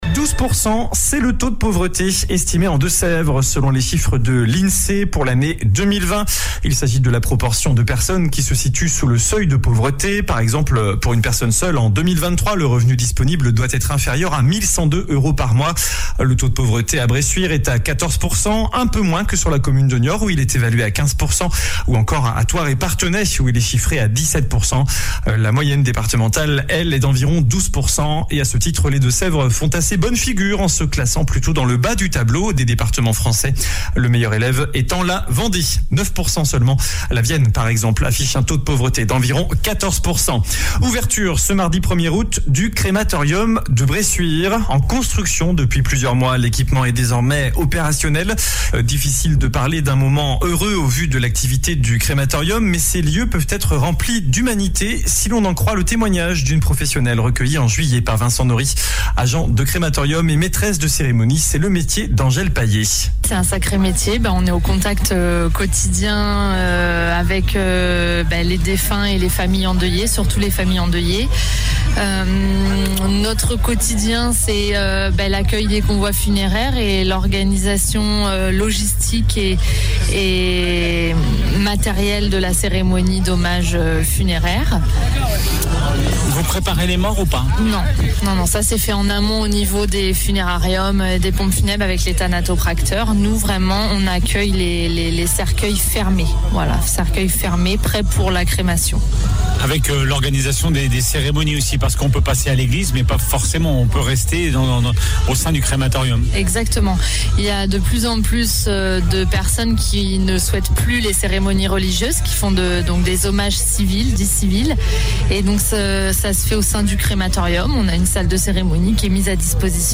JOURNAL DU MARDI 01 AOÛT ( MIDI )